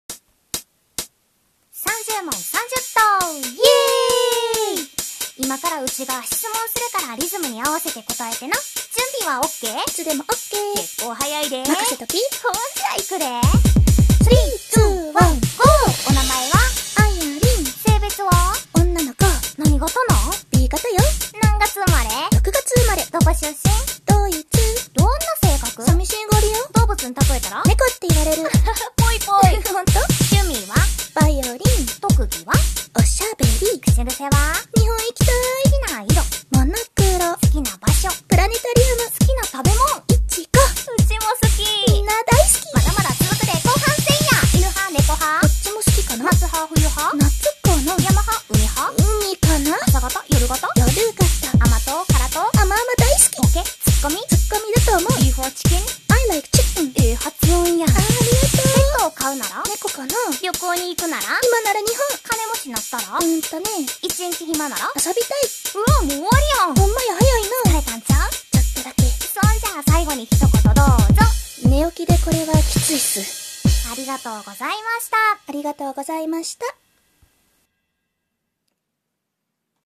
30問30答 関西弁で質問するで！を答えました(*´꒳`*)